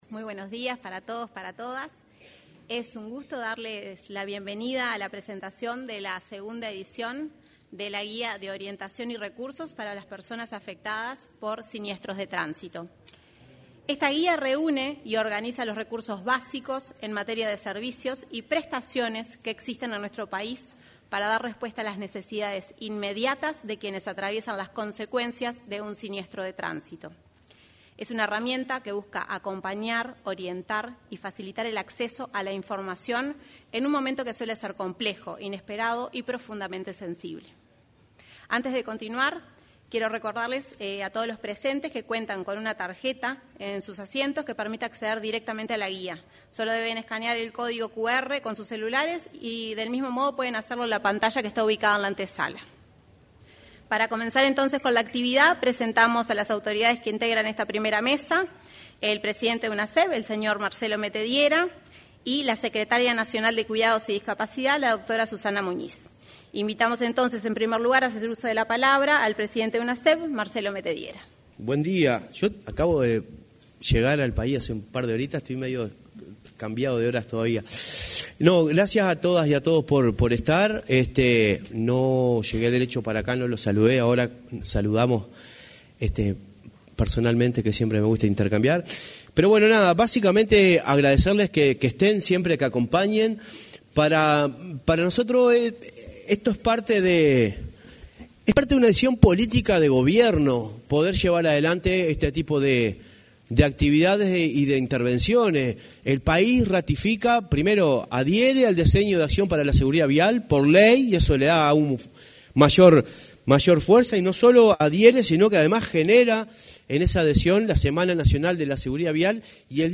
Se realizó la presentación de la “Guía de orientación y recursos para las personas afectadas por siniestros de tránsito” actualizada. En la oportunidad, se expresaron el presidente de la Unidad Nacional de Seguridad Vial, Marcelo Metediera, y la secretaria nacional de Cuidados y Discapacidad, Susana Muñiz.